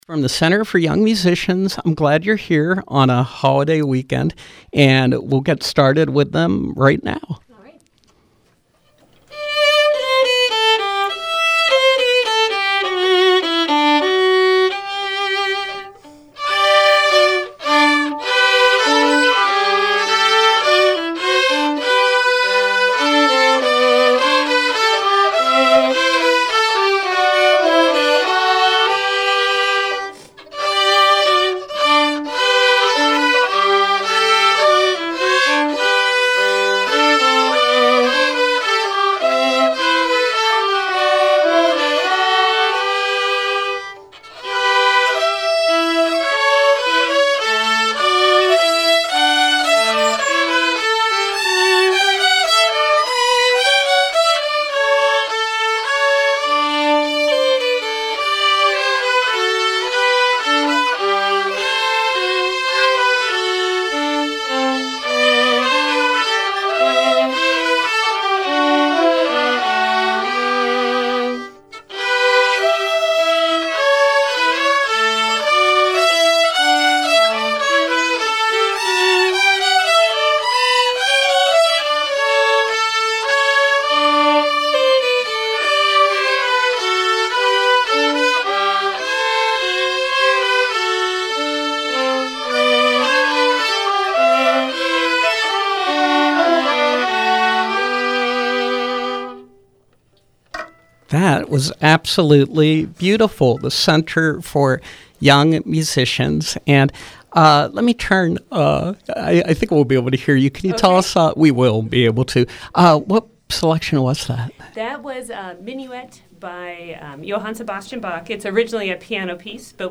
From 11-29-14: Four violinists (ages 7 to 10) from the Center for Young Musicians.